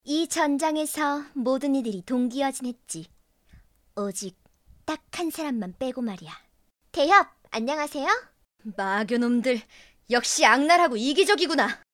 萝莉女声【角色配音】